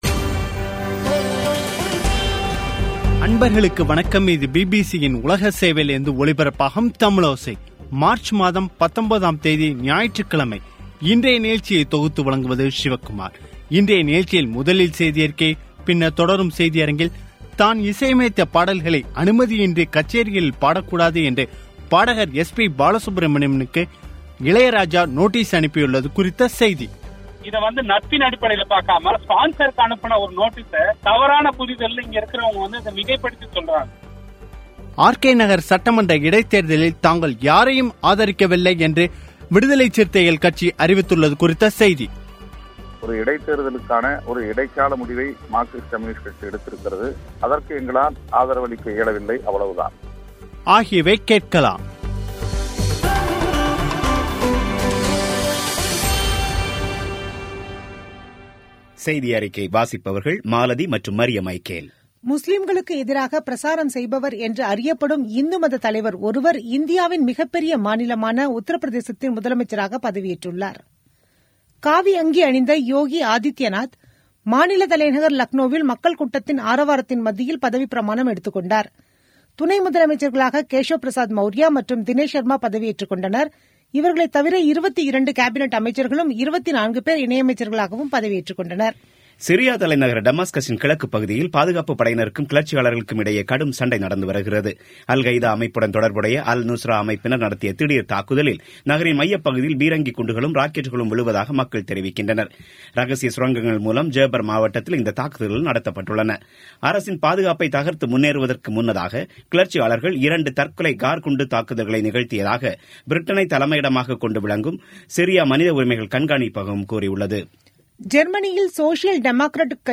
இன்றைய நிகழ்ச்சியில் முதலில் செய்தியறிக்கை, பின்னர் தொடரும் செய்தியரங்கில்